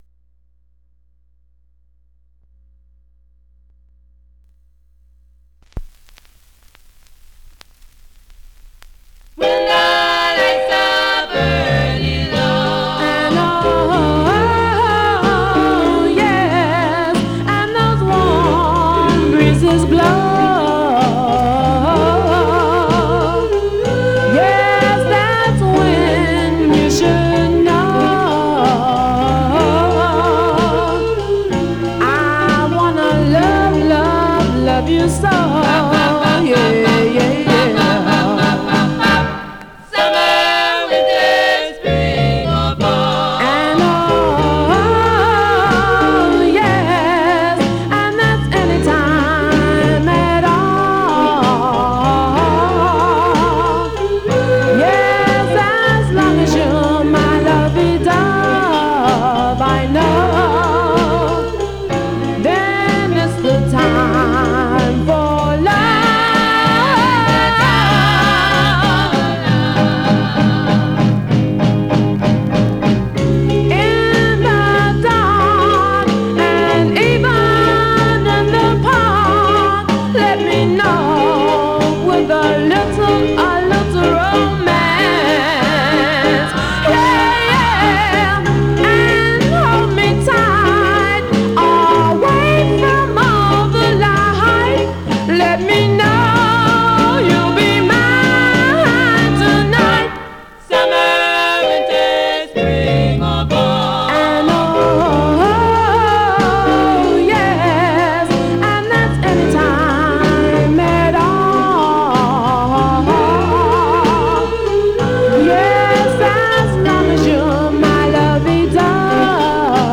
Surface noise/wear
Mono
Black Female Group